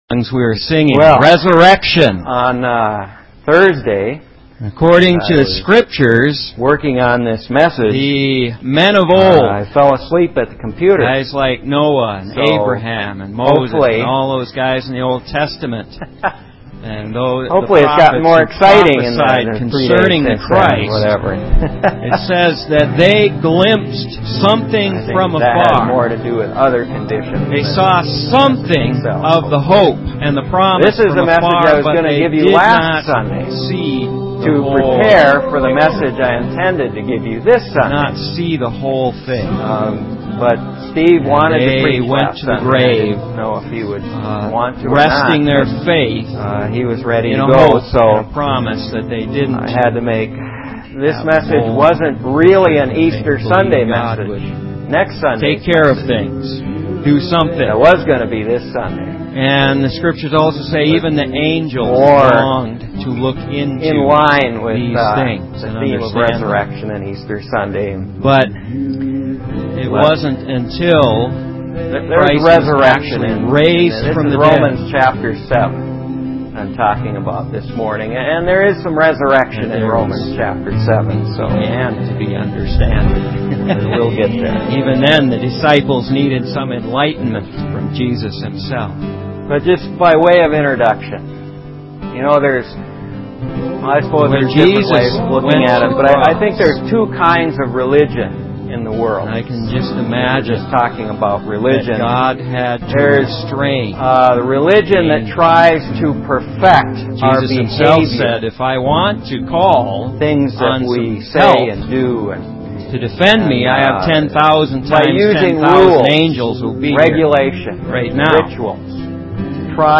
Message
on Easter Sunday